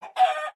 chicken
hurt1.ogg